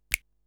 Snap Rnb.wav